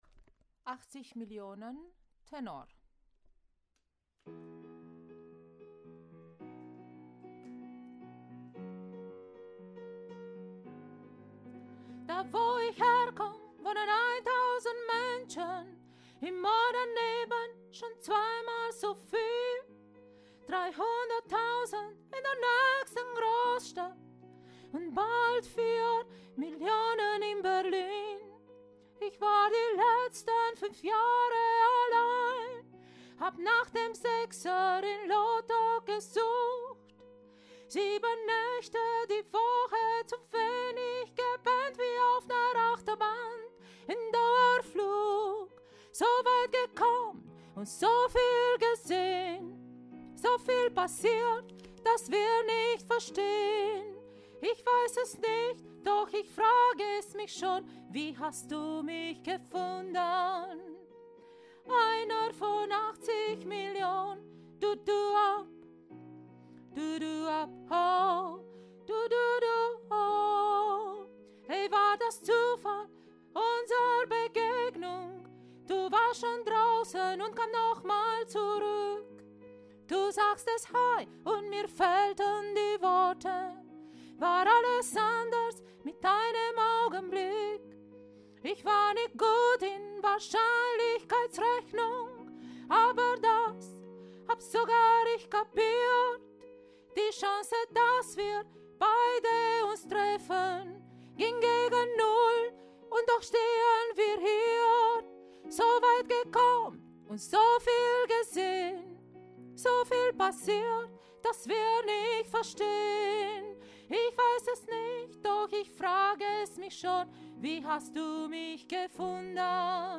80 Millionen – Tenor
80Millionen-Tenor.mp3